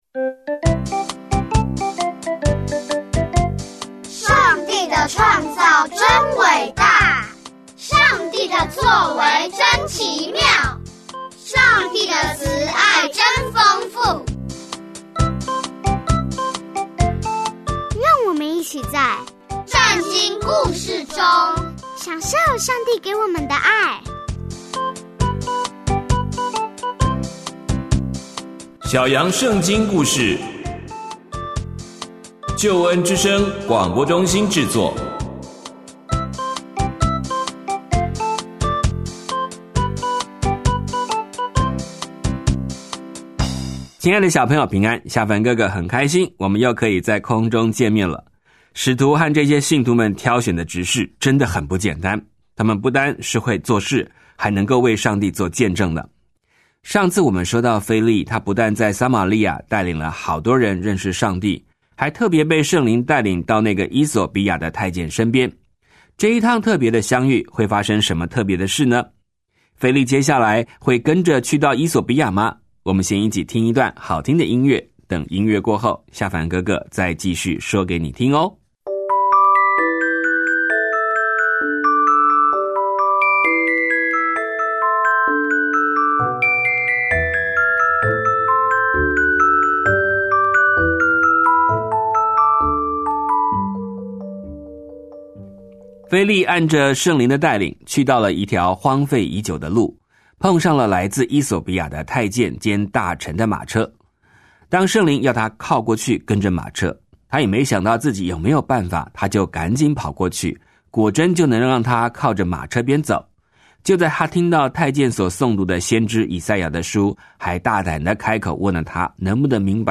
易懂好听的圣经故事（本节目由救恩之声制作）